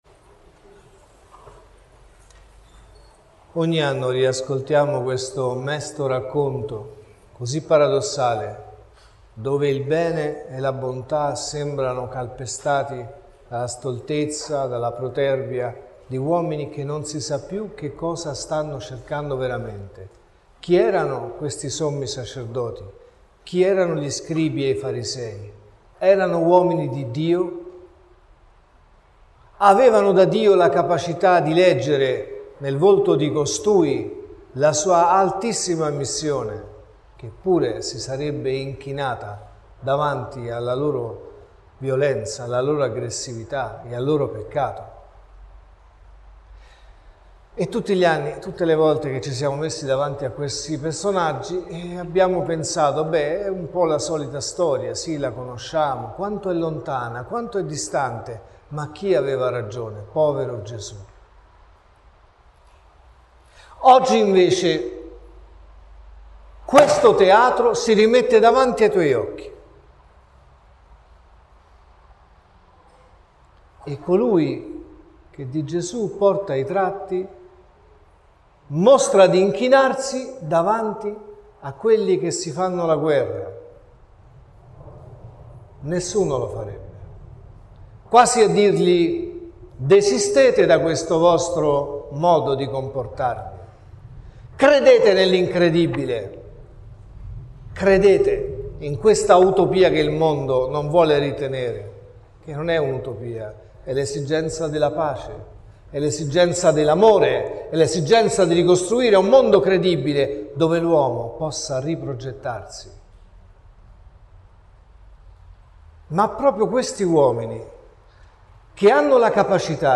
Messa Vespertina